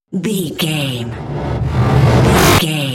Dramatic riser flashback
Sound Effects
In-crescendo
Atonal
tension
ominous
eerie